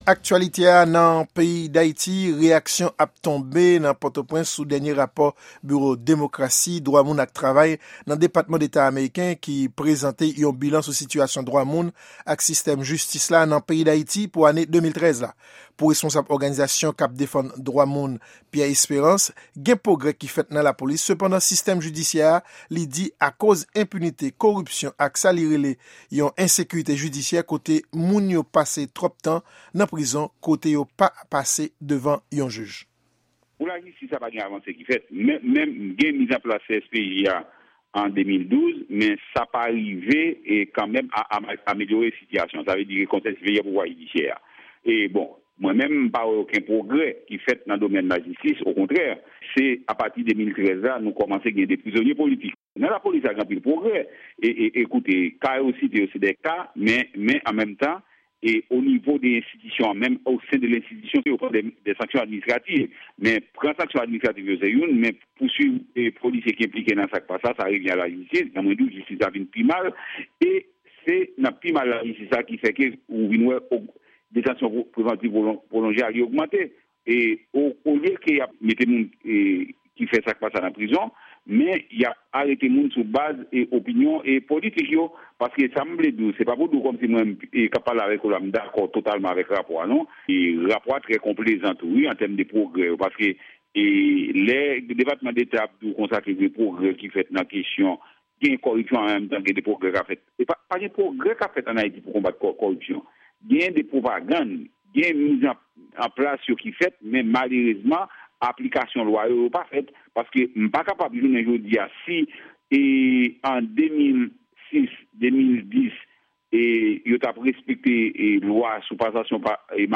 Repòtaj : Reyaksyon sou Rapò Depatman Deta konsènan Dwamoun ann Ayiti